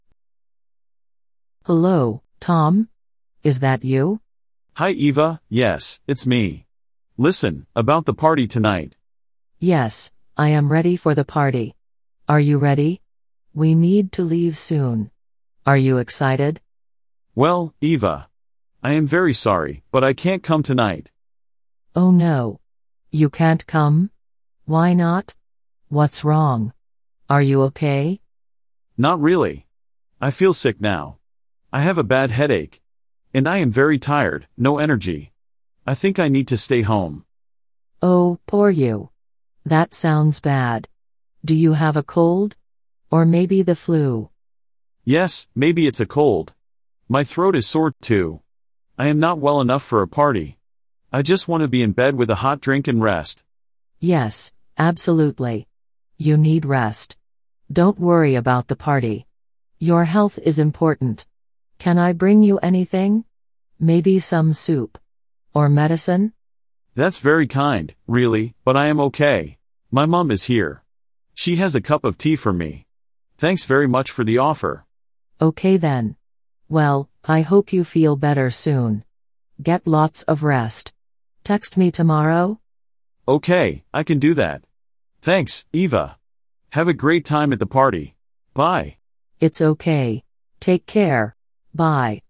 Beginner Listening Practice